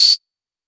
034 CR78 Tamb.wav